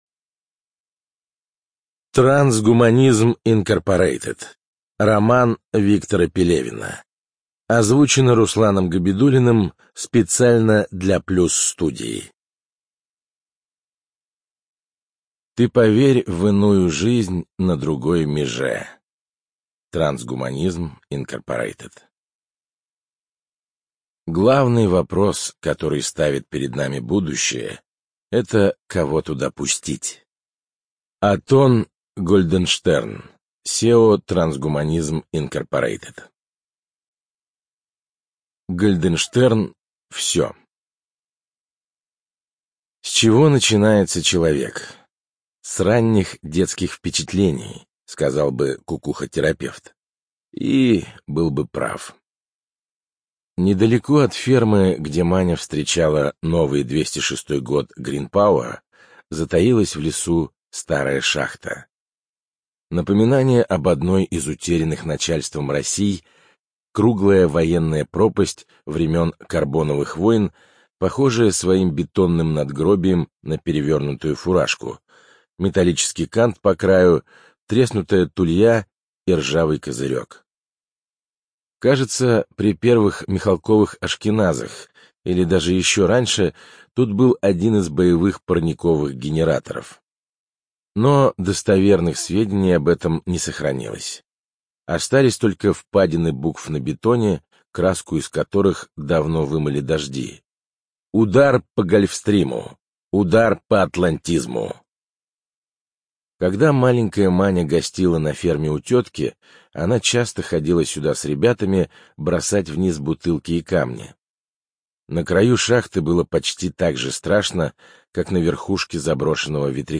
ЖанрСовременная проза